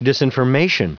Prononciation du mot disinformation en anglais (fichier audio)